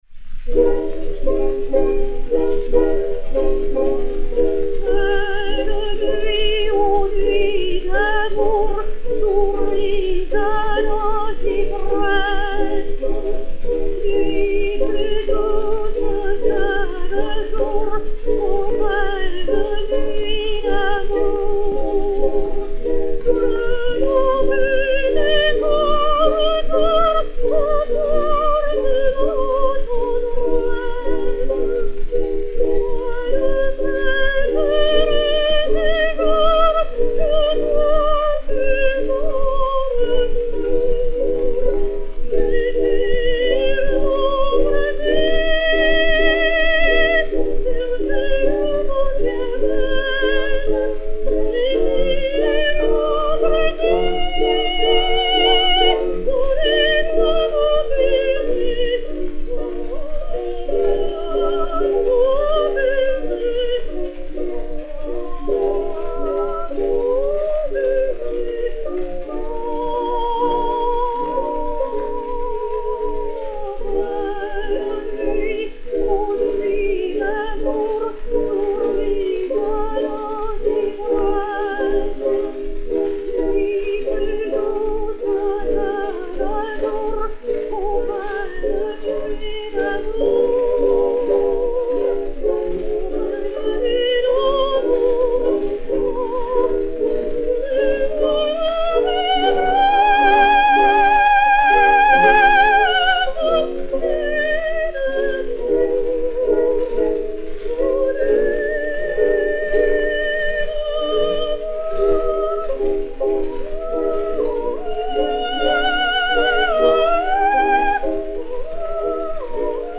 Emma Calvé et Orchestre
Pathé saphir 0275, mat. 2303, enr. Paris juin/juillet 1920